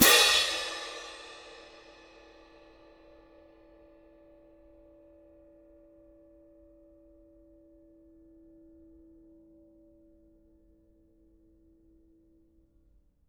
cymbal-crash1_ff_rr2.wav